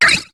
Cri de Lovdisc dans Pokémon HOME.